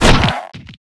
tug.wav